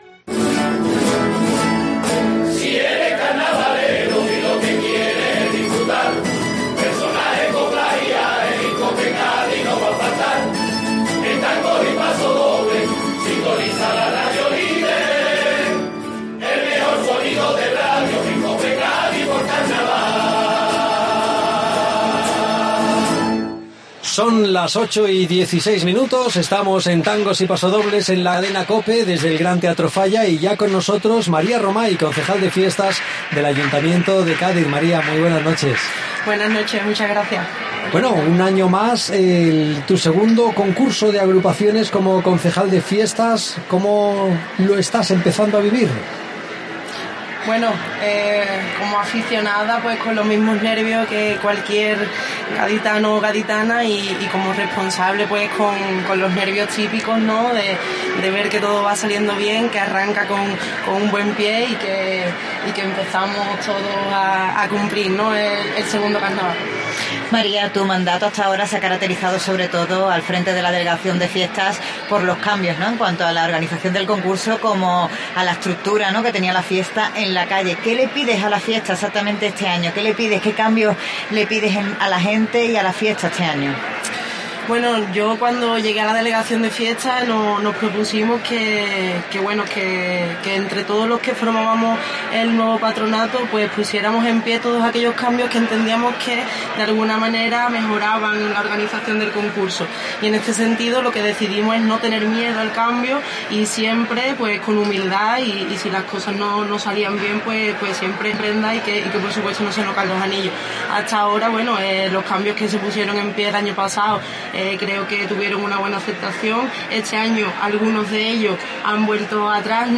Maria Romay en el palco de COPE desde el Falla